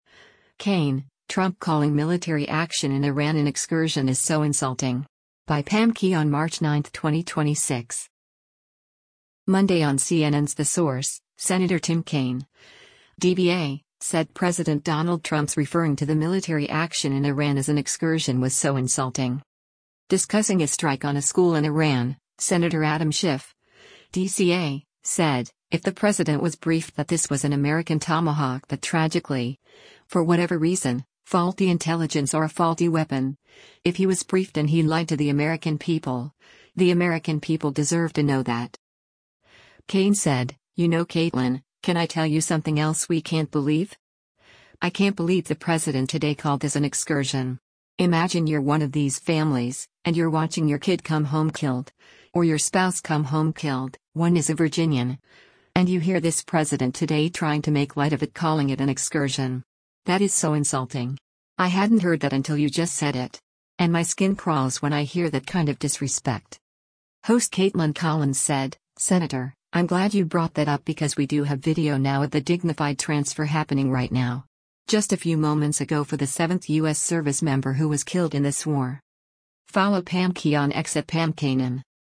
Monday on CNN’s “The Source,” Sen. Tim Kaine (D-VA) said President Donald Trump’s referring to the military action in Iran as an excursion was “so insulting.”